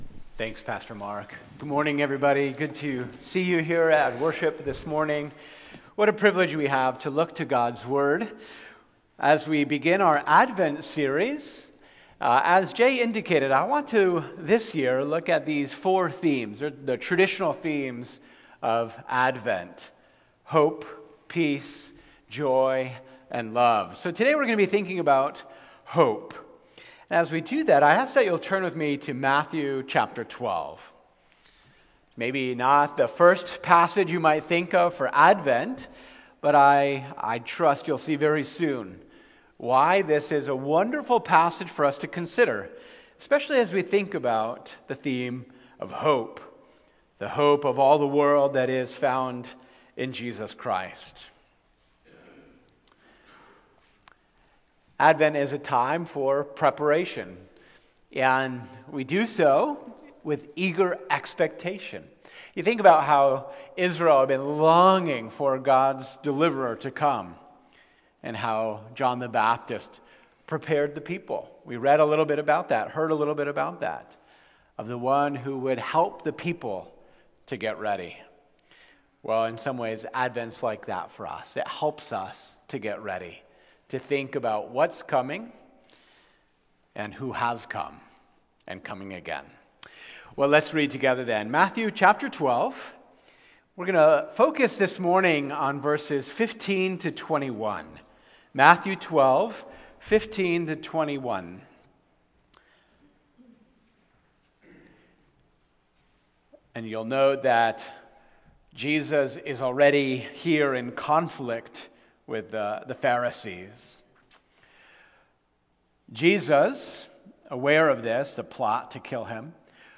Sermons — Cornerstone Christian Church